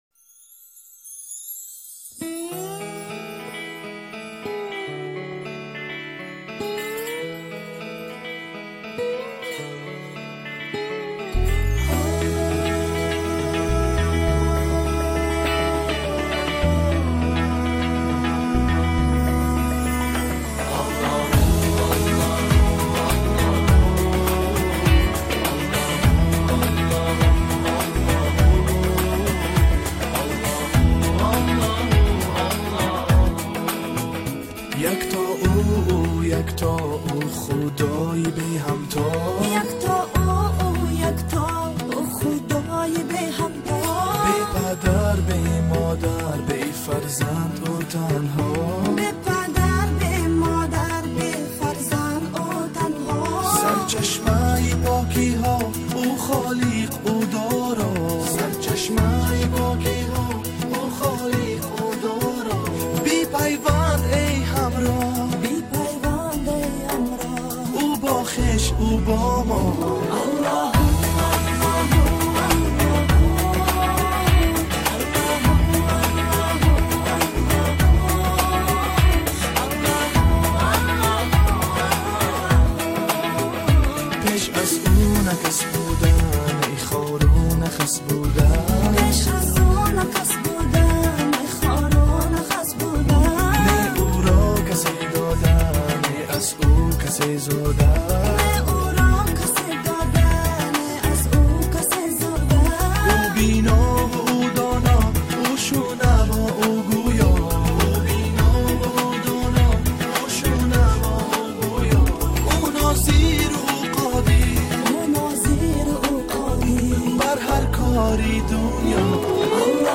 Главная » Файлы » Каталог Таджикских МР3 » Эстрада